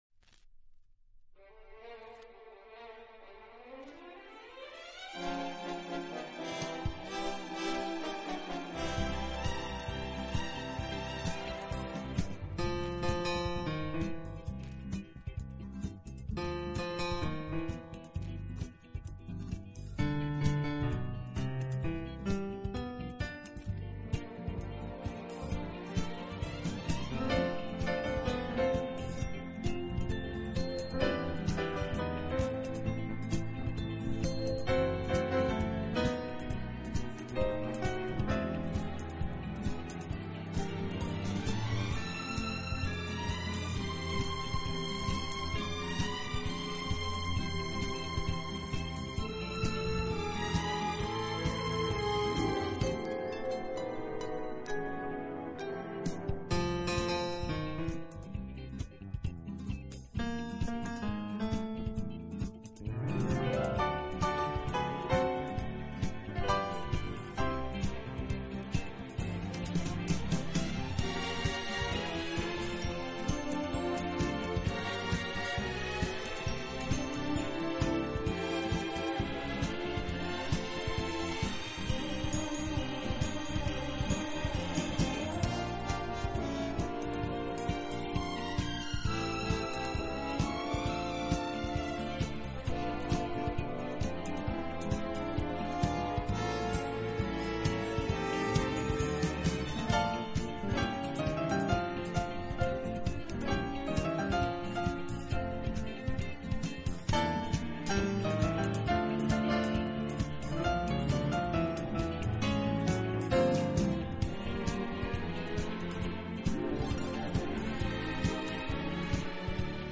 【双钢琴】
除此之外，他在选择乐队伴奏时也非常考究，常使用弦乐来